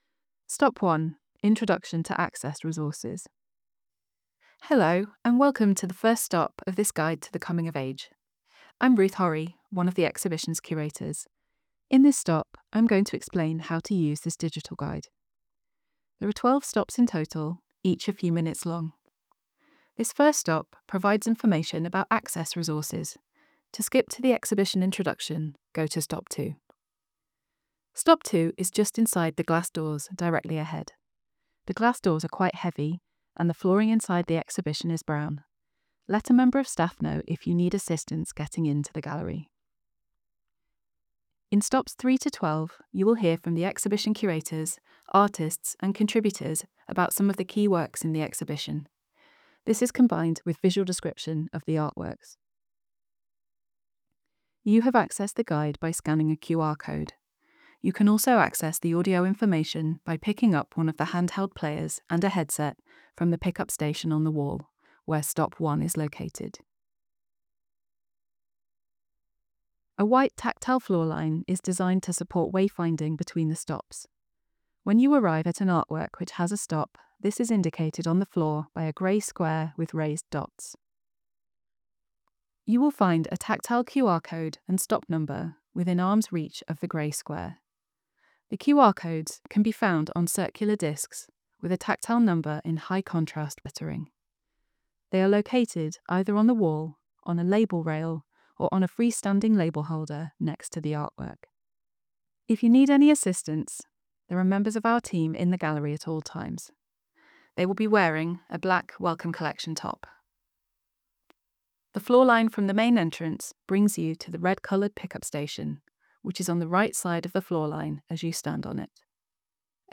Explore our exhibitions using your own device, with audio description, British Sign Language and gallery captions